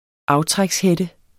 Udtale [ ˈɑwtʁags- ]